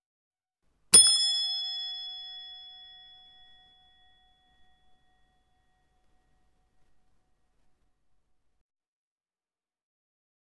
hotel-bell-2
bell chrome ding foley hotel metal ping reception sound effect free sound royalty free Sound Effects